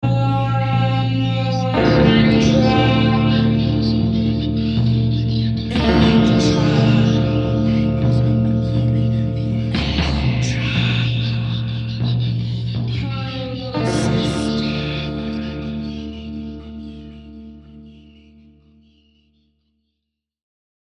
Liars & Believers transforms ancient, divine justice into contemporary, thrashing vengeance – in a new theatrical adaptation with driving text, kinetic physicality, and a Riot-Grrrl punk band.
These are the first demo recordings